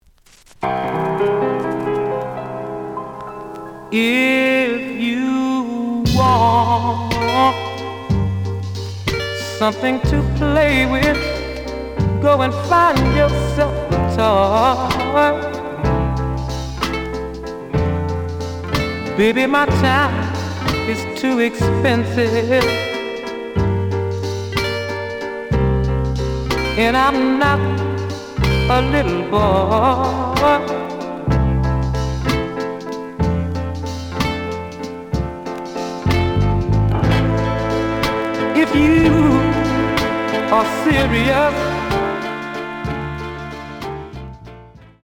The audio sample is recorded from the actual item.
●Genre: Soul, 60's Soul
Slight noise on beginning of A side, but almost good.)